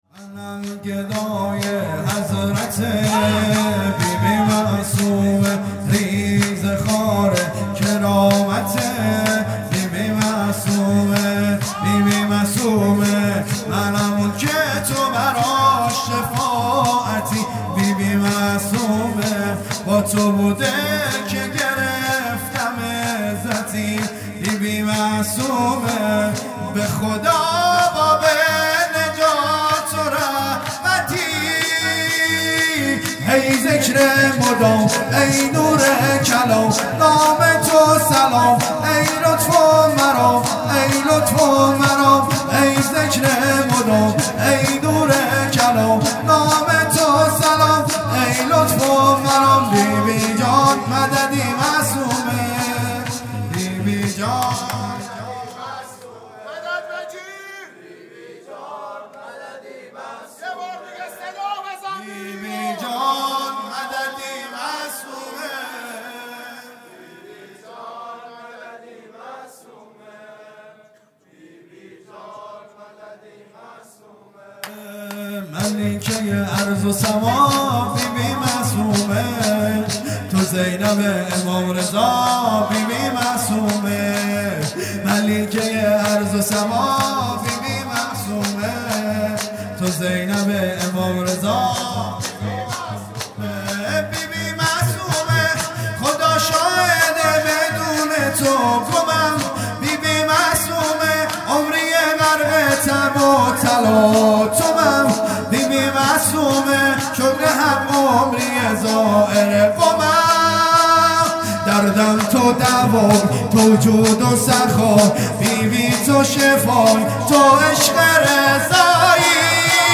شور
وفات حضرت معصومه (س) | ۲۷ آذر ۹۷